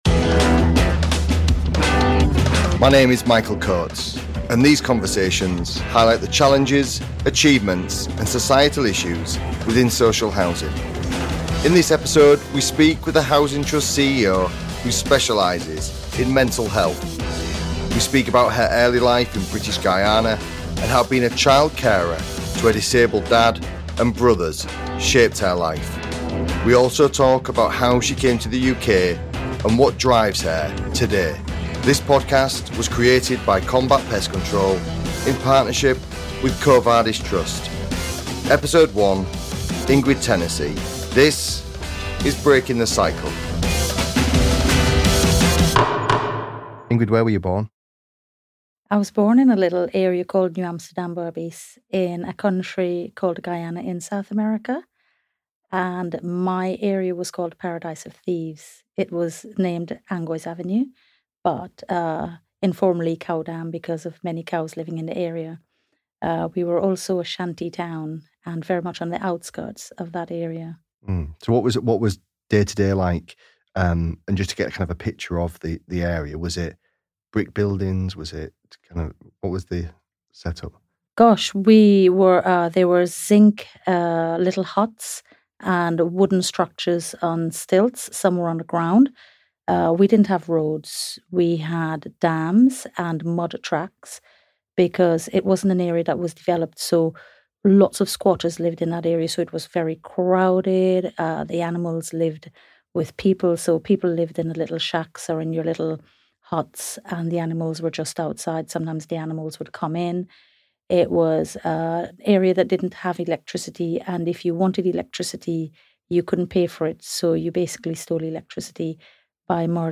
From her humble beginnings to how she got to the UK, this conversation is open, honest and inspirational.